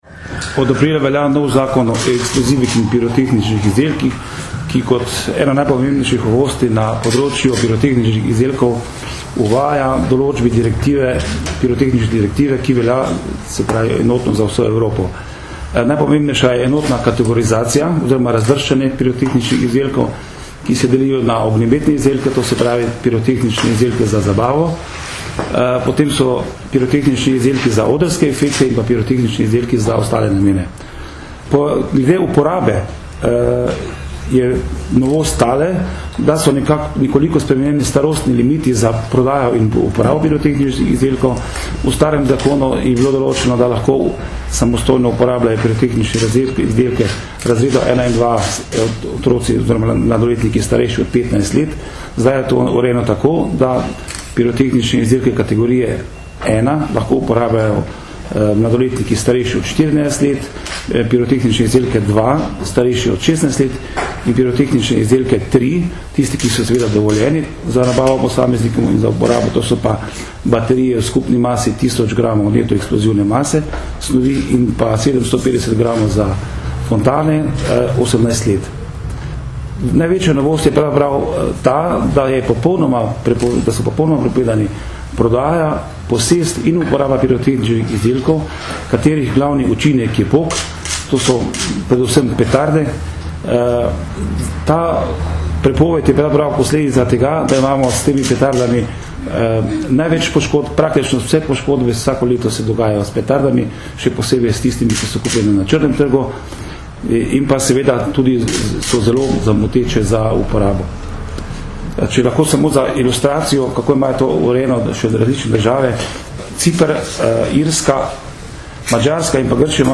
Na današnji novinarski konferenci so predstavniki policije in inšpektorata Republike Slovenije za notranje zadeve na kratko predstavili novosti, ki jih prinaša novi Zakon o eksplozivih in pirotehničnih izdelkih.